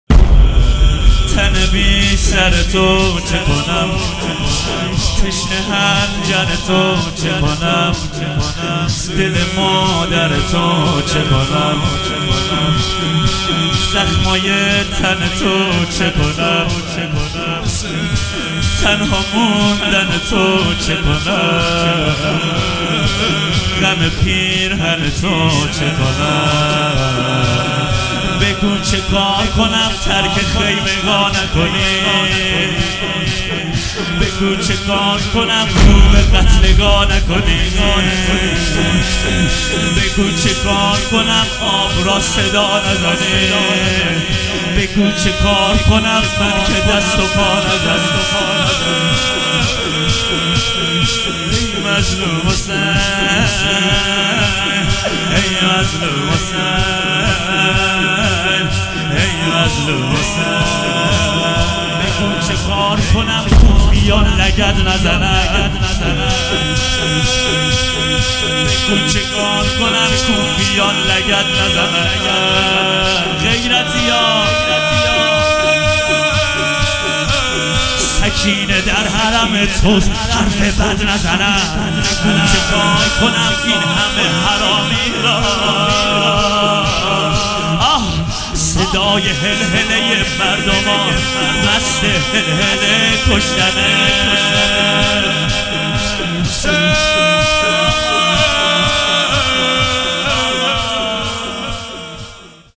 مداحی گودال